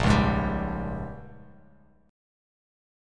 Bbm13 chord